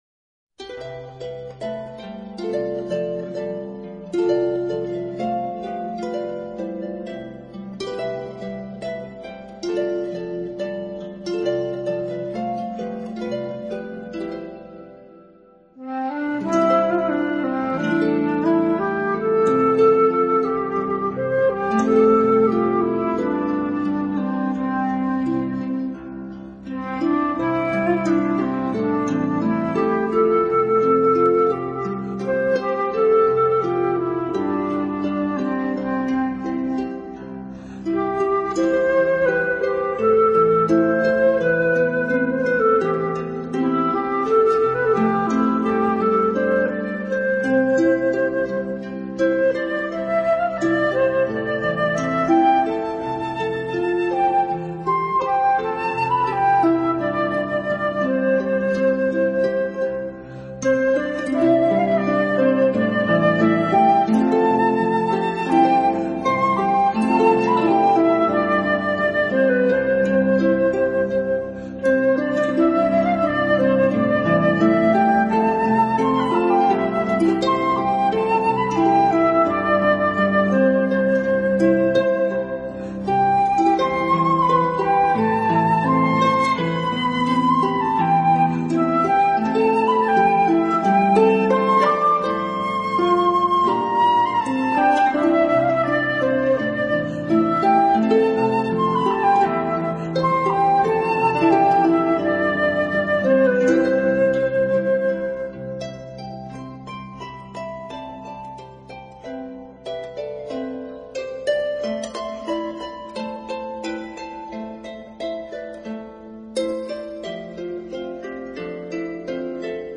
【长笛专辑】